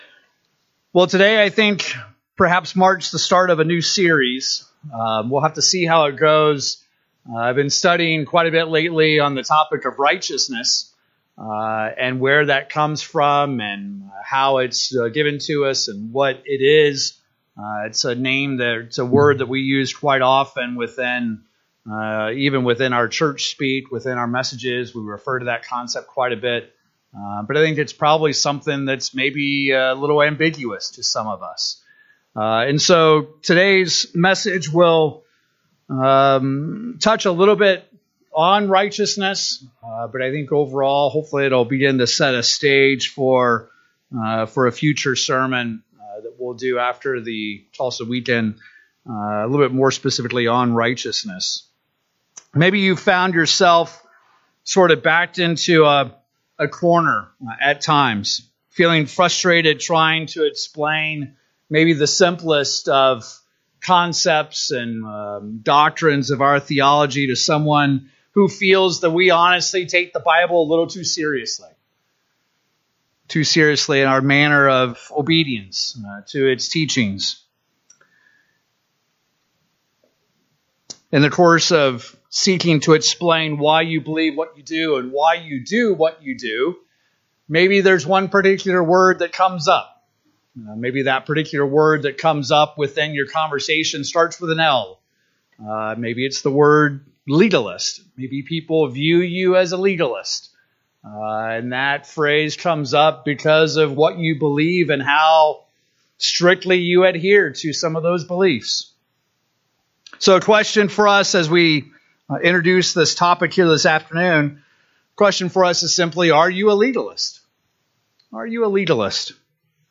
So how does the Bible define a legalist? In this sermon we will explore three ways the Bible defines what a legalist is.